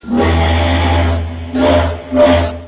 Now cut down to a heavy duty freight carrying barge, the CHIEF once boasted a classic old fashioned deep throated steam whistle. If you have a REAL ONE PLAYER on your computer you can actually listen to that whistle which was a familiar sound to residents of Mackinaw City and St. Ignace, Michigan for over 60 years.
chief_whistle.ra